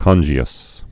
(kŏnjē-əs)